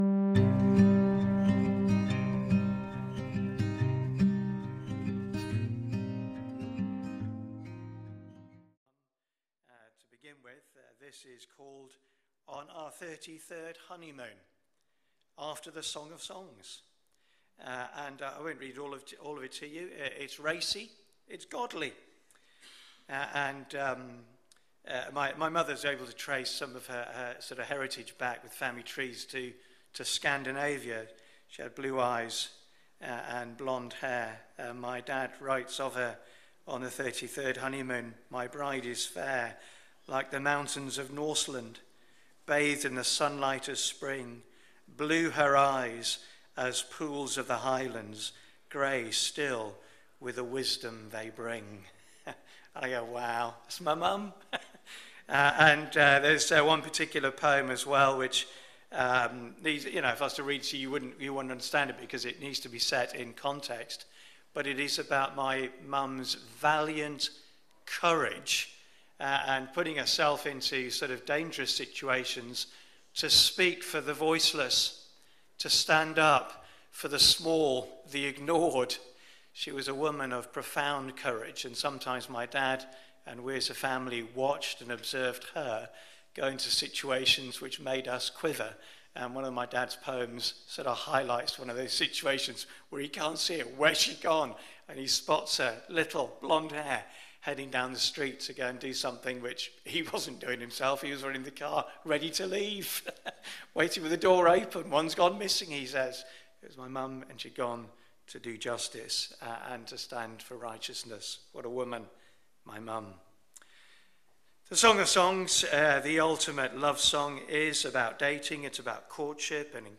Bible Talks | Christ Church Central | Sheffield